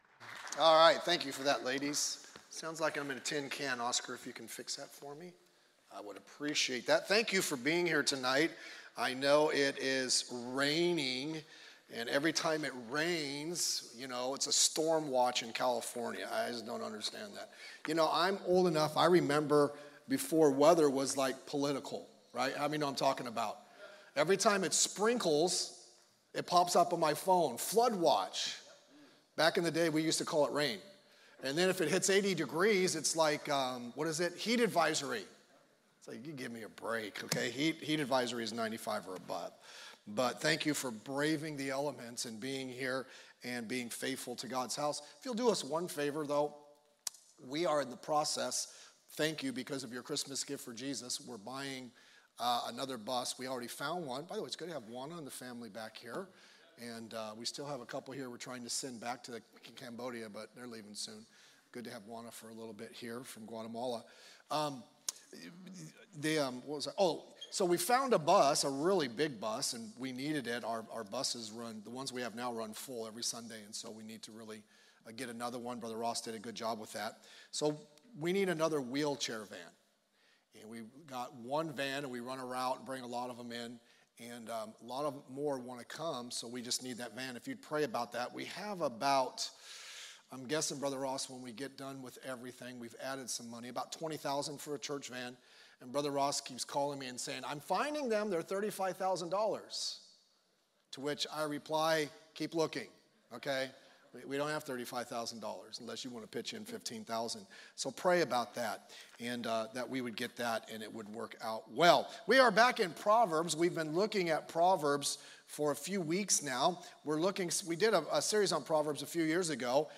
practical preaching